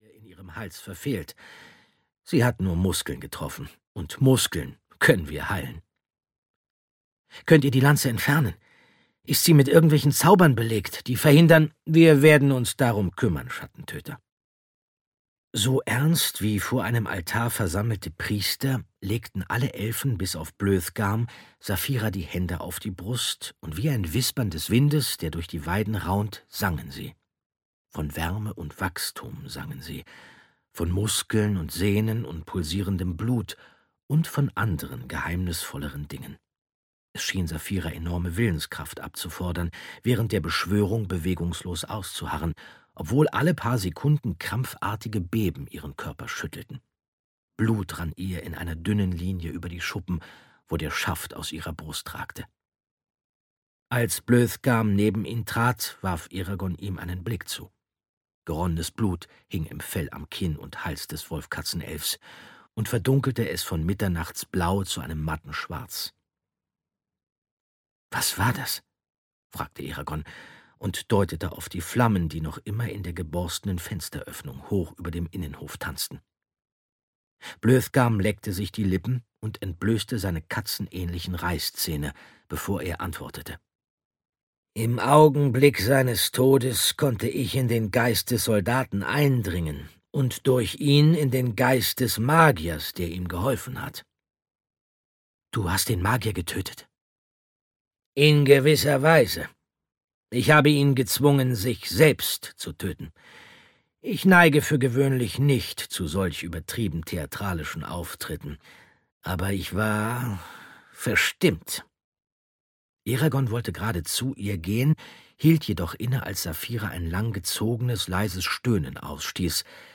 Audio knihaEragon - Das Erbe der Macht (DE)
Ukázka z knihy
• InterpretAndreas Fröhlich